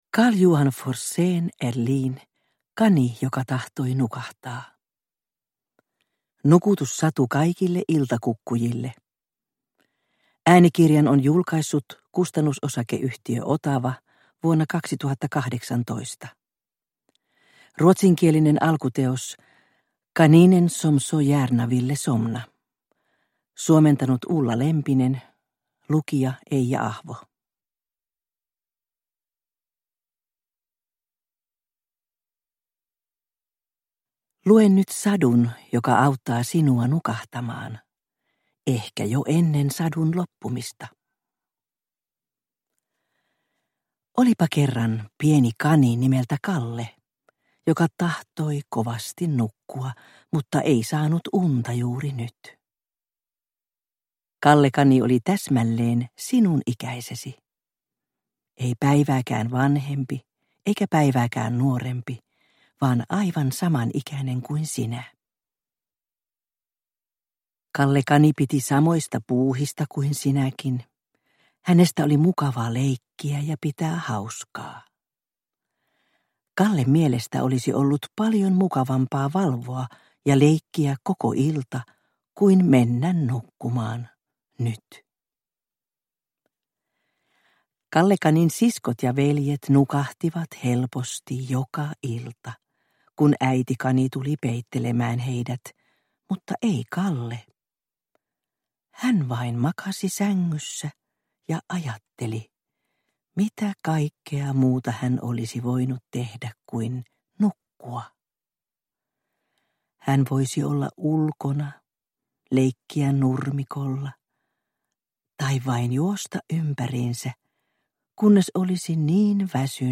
Kani joka tahtoi nukahtaa – Ljudbok – Laddas ner